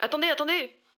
VO_ALL_Interjection_03.ogg